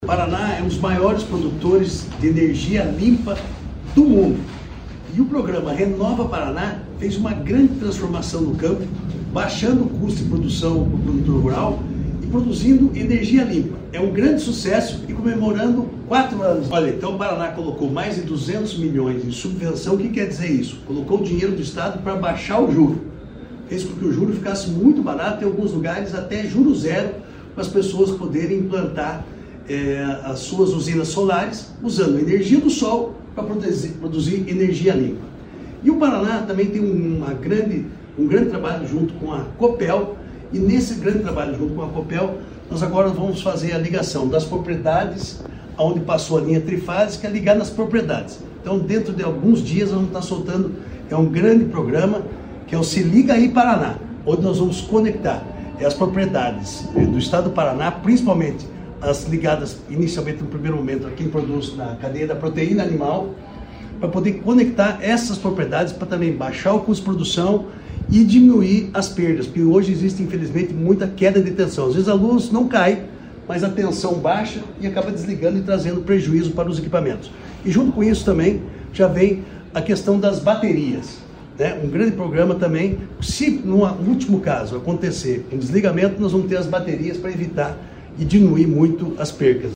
Sonora do secretário Estadual da Agricultura e Abastecimento, Marcio Nunes, sobre os quatro anos do RenovaPR